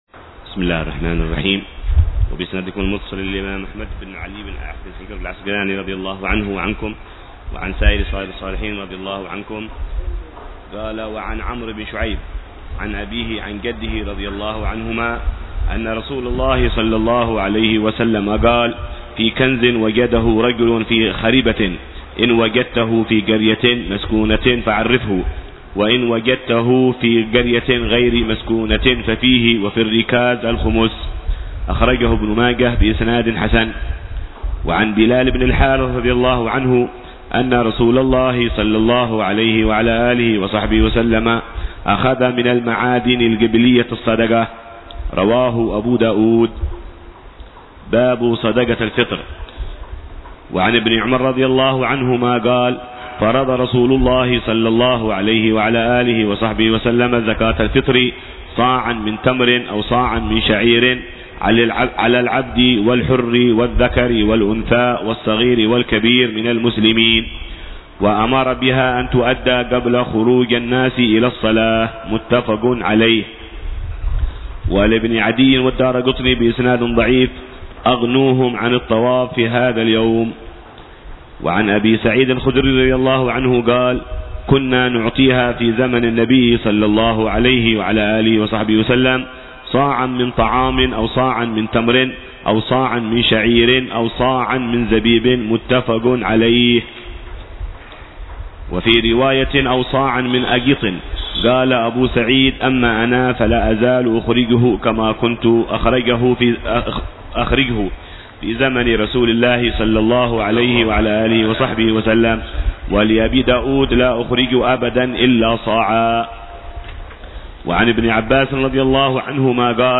شرح الحبيب عمر بن حفيظ على كتاب بلوغ المرام من أدلة الأحكام للإمام الحافظ أحمد بن علي بن حجر العسقلاني،